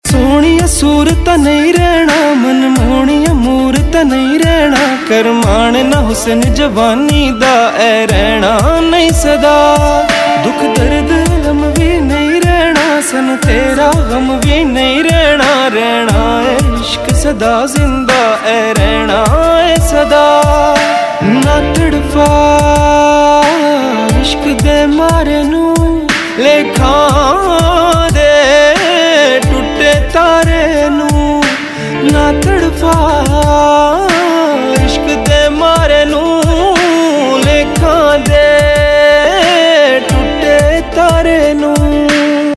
HomeRingtones Mp3 > Punjabi Mp3 Tone